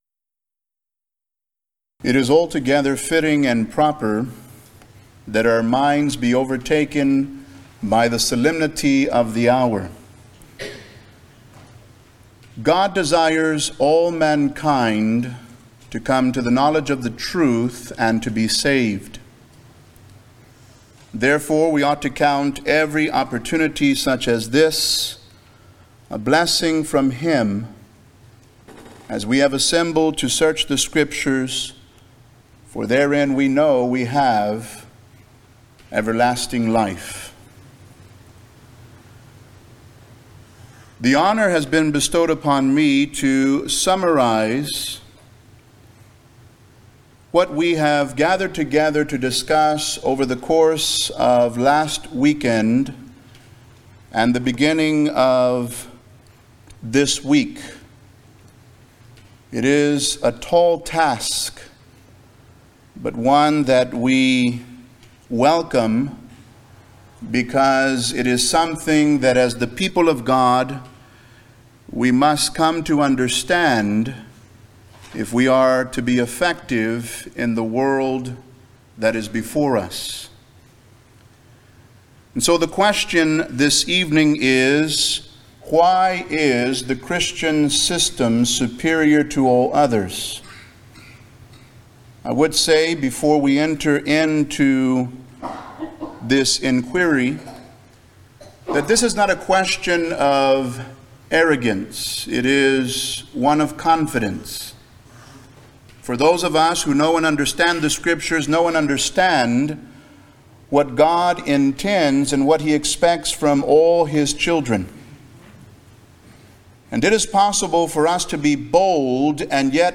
Event: 28th Annual Shenandoah Lectures
lecture